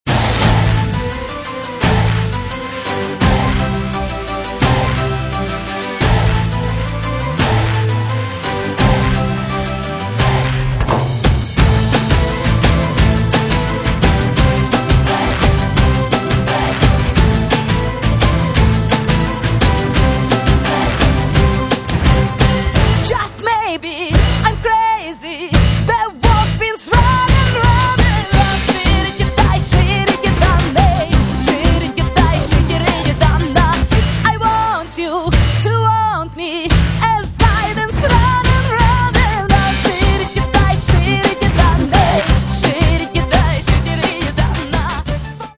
Каталог -> Поп (Легка) -> Етно-поп